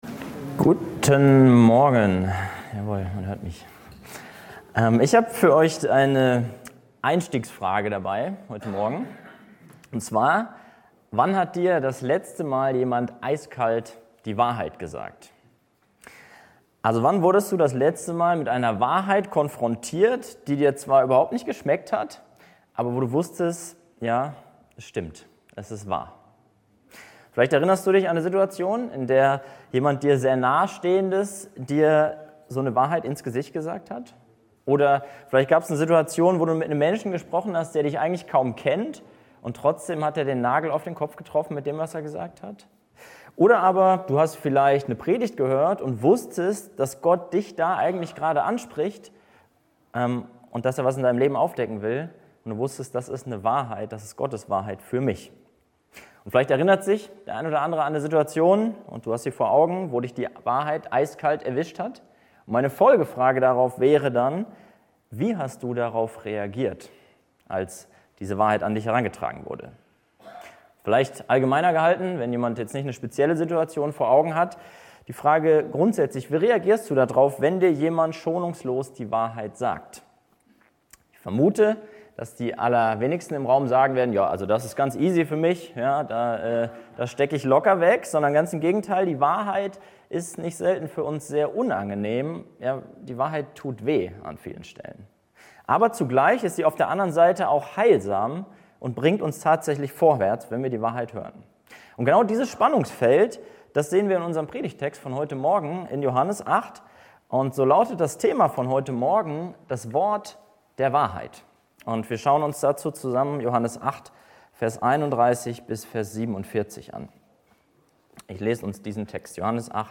Die Punkte der Predigt waren: Die Wahrheit macht frei (Verse 31-36) Die Wahrheit entlarvt die Lüge (Verse 37-44) Die Wahrheit erfordert Glauben (Verse 45-47) HauskreisLeitfaden Aufnahme (MP3) 38 MB PDF 565 kB Zurück Timotheus - jemand, dem der Mut fehlt Weiter Jesus ist aller Ehre wert